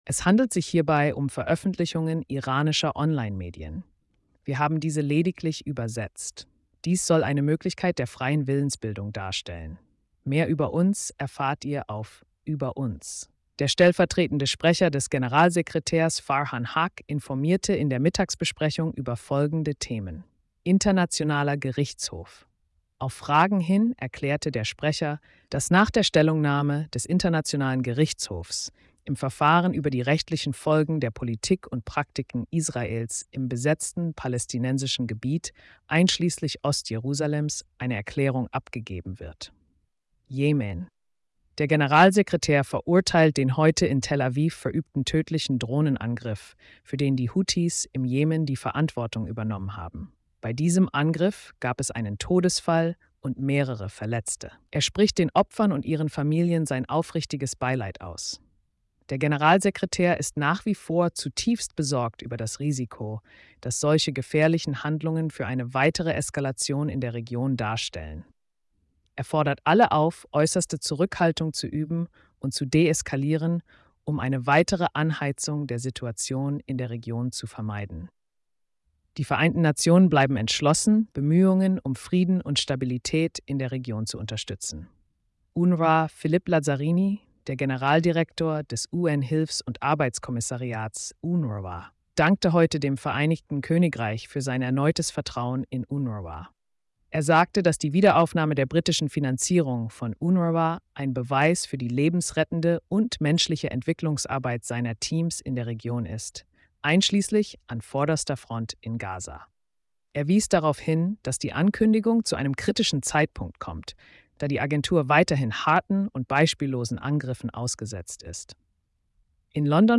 Internationales Gericht, Ukraine & mehr – Tägliche Pressekonferenz (19. Juli 2024) | UN
Der stellvertretende Sprecher des Generalsekretärs, Farhan Haq, informierte in der Mittagsbesprechung über folgende Themen: